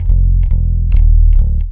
BASS 2 139-L.wav